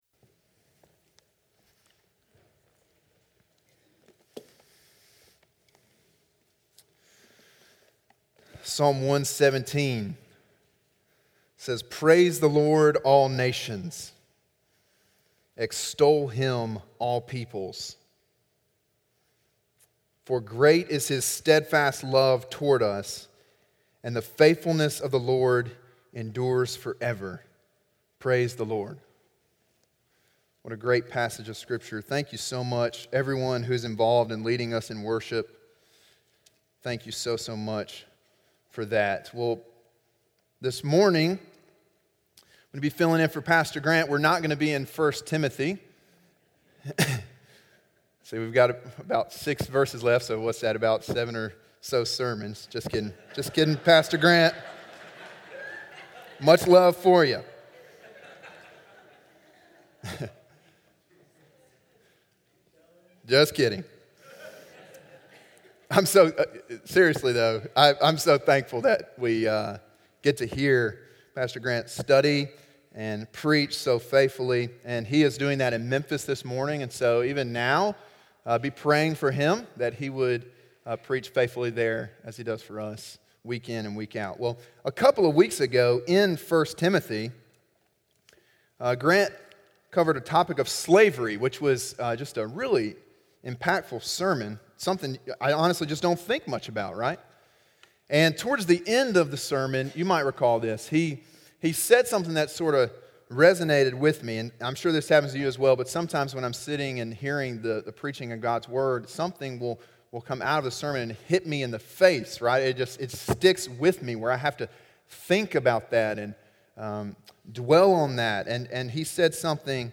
Sermon: “Slaves of Righteousness” (Romans 6:15-23)